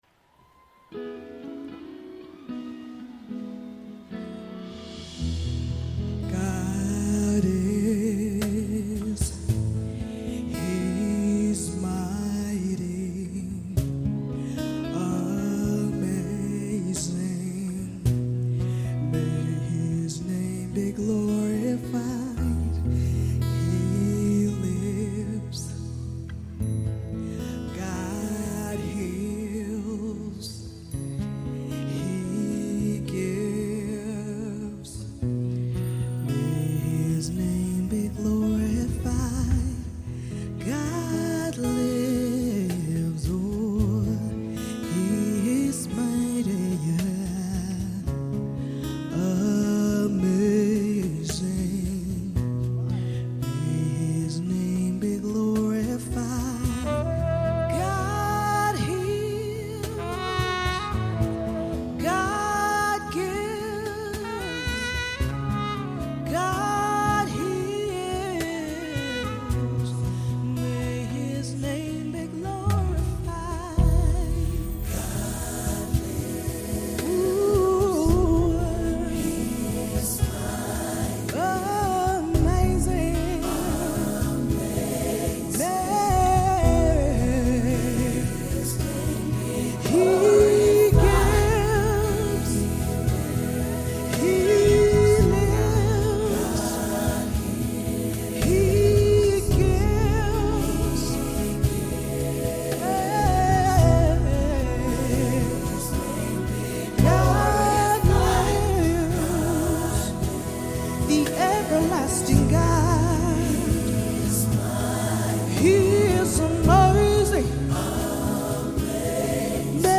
February 21, 2025 Publisher 01 Gospel 0